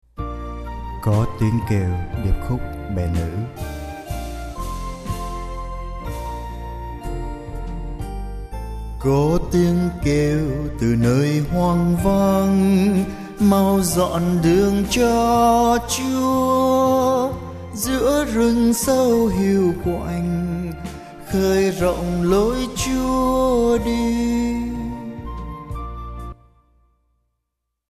CoTiengKeu_DK_Sop.mp3